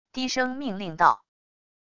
低声命令道wav音频
低声命令道wav音频生成系统WAV Audio Player